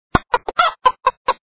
1 channel
5_s_kv_slepice.mp3